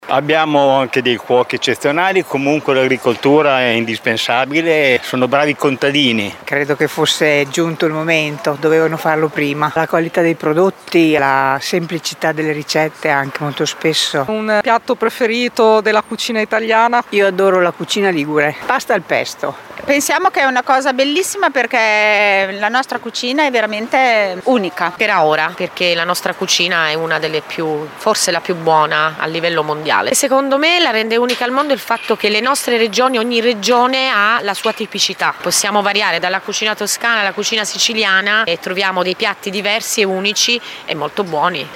vox-cucina.mp3